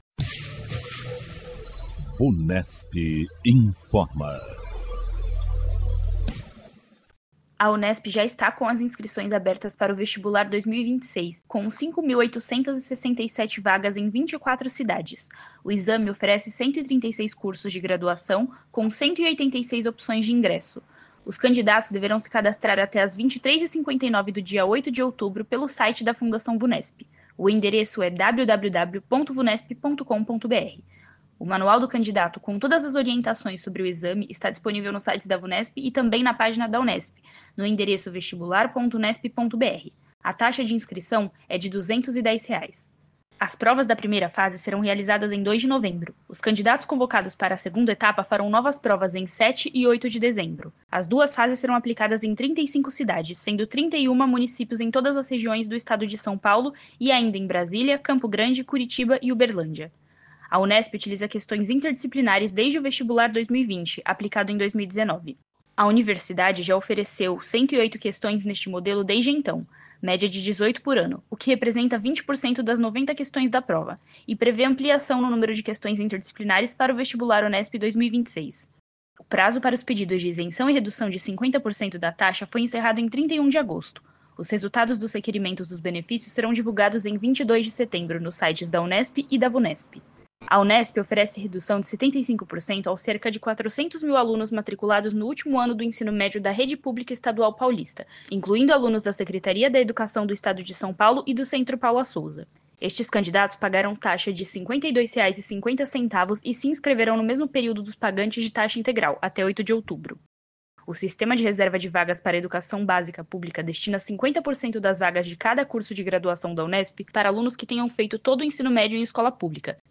A equipe de jornalistas da Vunesp apresenta as últimas informações sobre concursos, vestibulares e avaliações feitas pela Instituição.